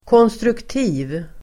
Uttal: [kån:strukt'i:v el. k'ån:-]